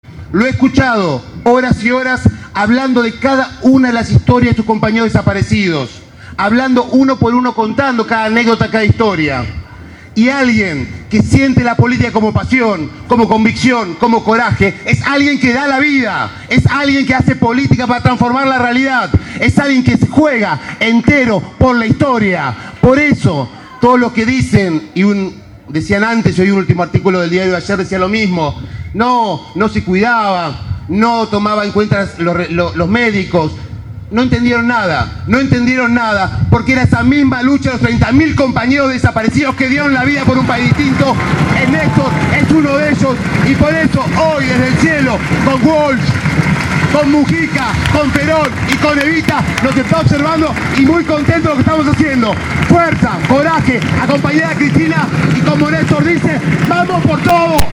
Al cumplirse un mes de su fallecimiento, La Cámpora organizó en la ciudad de La Plata un acto central en homenaje al ex Presidente.
También podemos escuchar a Juan Manuel Abal Medina, orador central, en tres fragmentos de su discurso